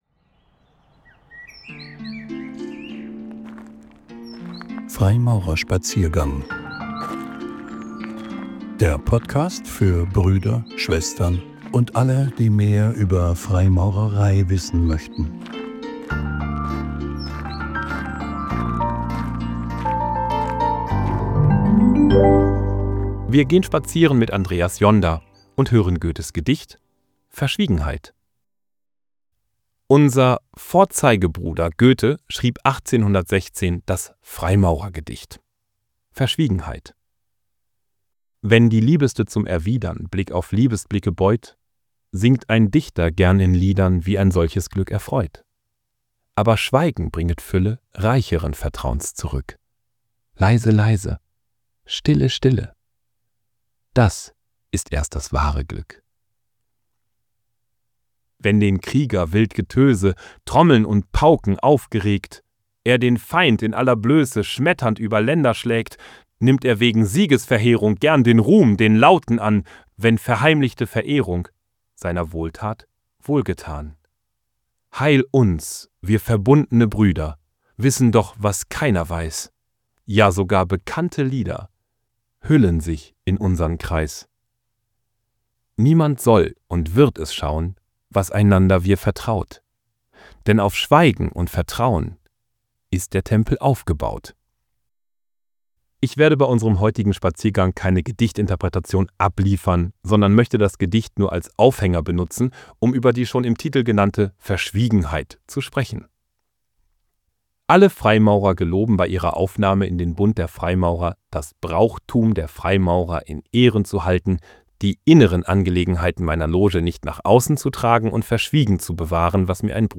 Ausgewählte "Zeichnungen" (Impulsvorträge) von Freimaurern.